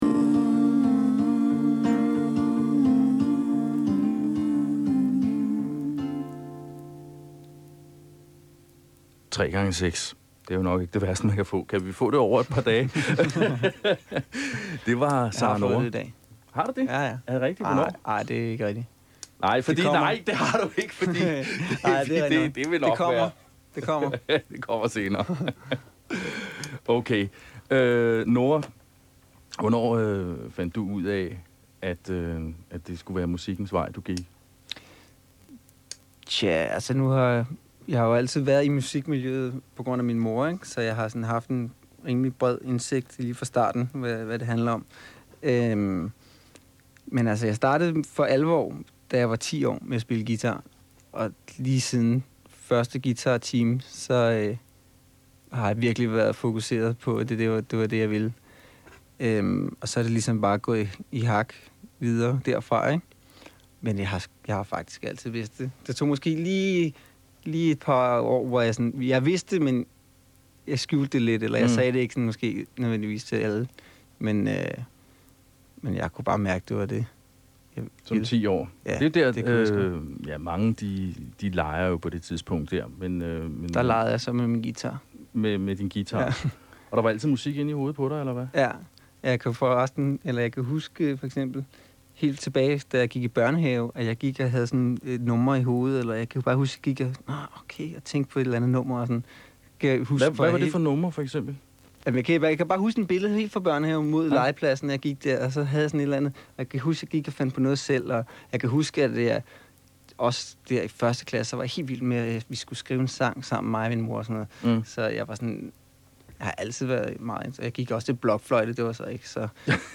P3 interwiev